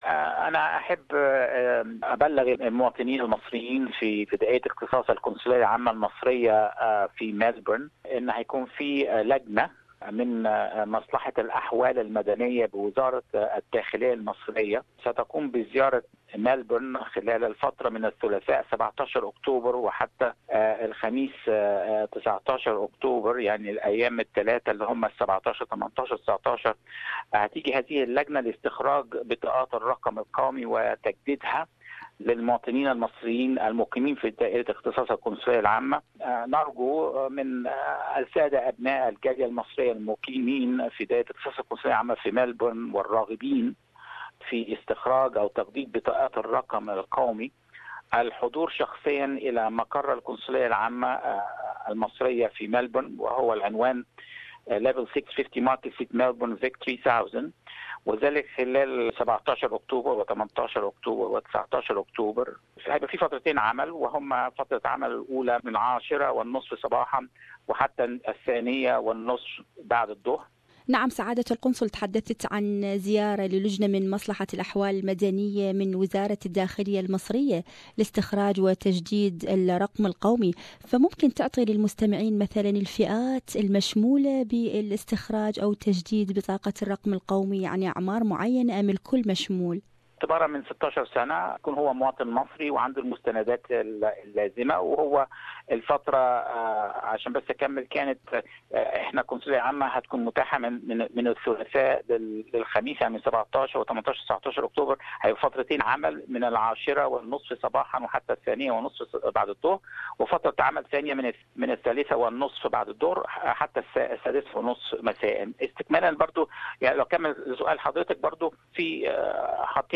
A committee from the ministry of Interior in Egypt will visit Melbourne on the 17th, 18th and 19th of October to issue and renew the national numbers for the Egyptian community in Melbourne. More on this issue, listen to this interview